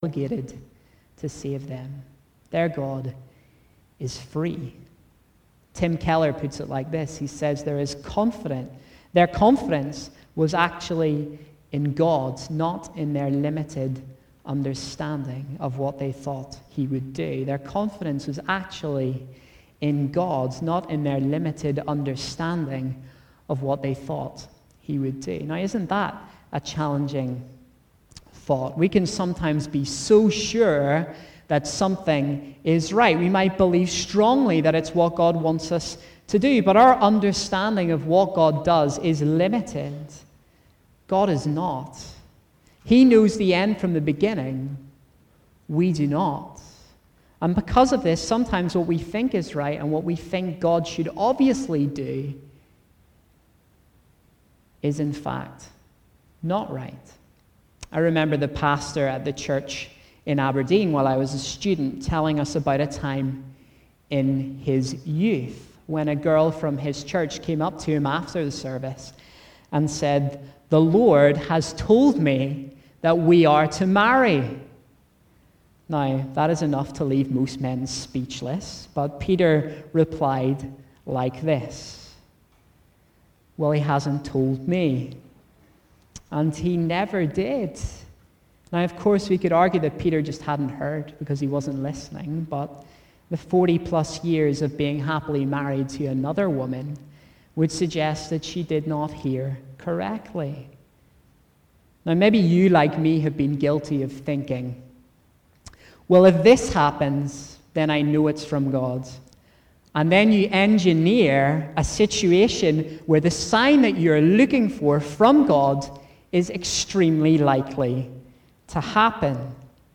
A message from the series "Daniel."